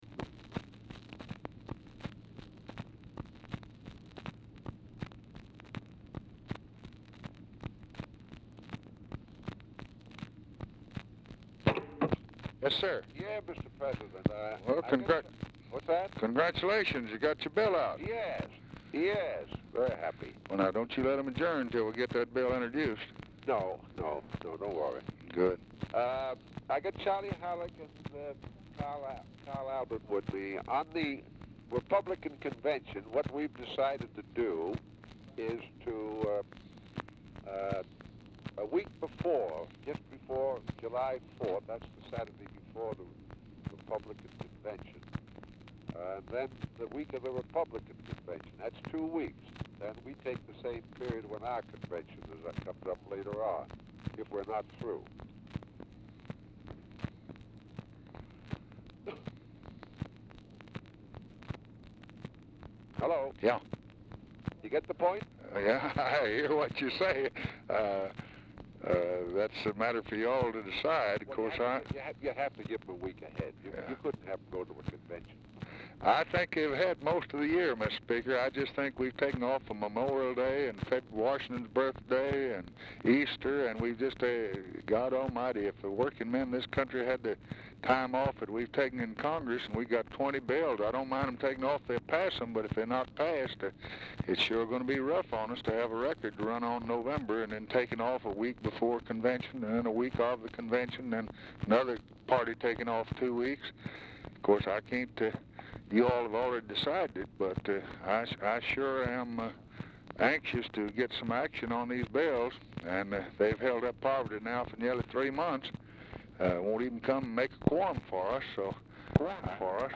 Telephone conversation # 3509, sound recording, LBJ and JOHN MCCORMACK, 5/26/1964, 12:50PM | Discover LBJ
Format Dictation belt
Specific Item Type Telephone conversation